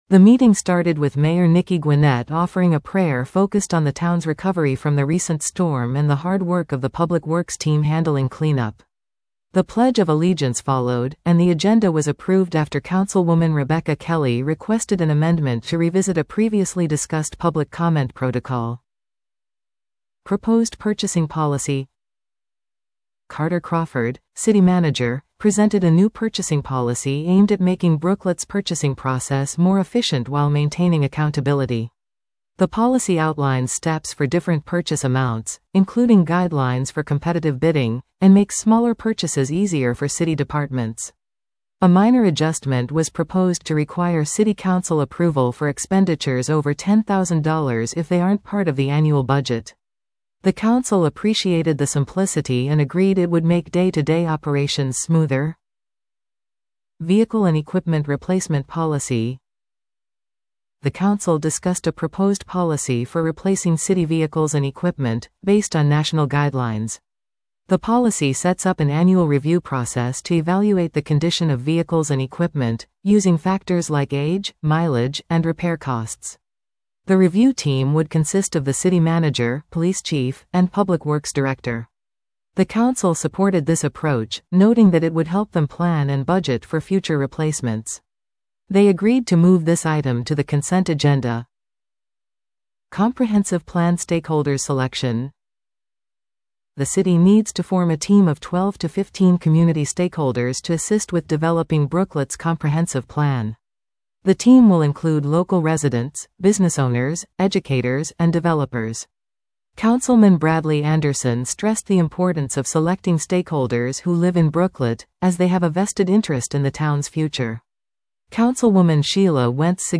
The Brooklet City Council’s October 10, 2024, work session focused on several key issues, including preparations for the upcoming SPLOST 2024 referendum and the selection of stakeholders for the town's comprehensive plan. The council also reviewed new purchasing and vehicle replacement policies, discussed tree removal efforts, and debated how best to recognize city employees during the holidays.
The meeting started with Mayor Nicky Gwinnett offering a prayer focused on the town’s recovery from the recent storm and the hard work of the public works team handling cleanup.